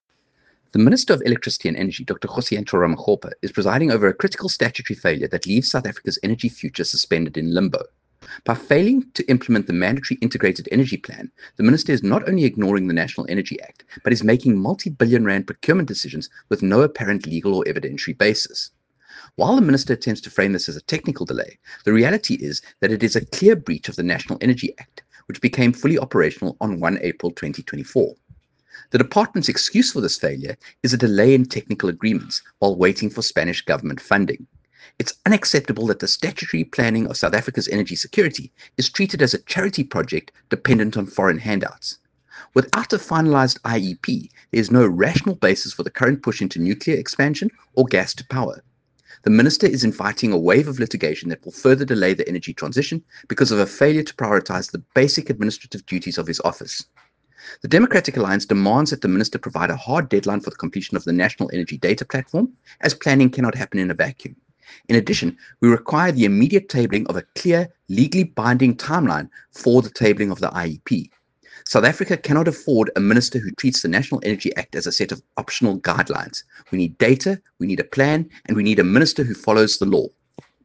soundbite by Kevin Mileham MP.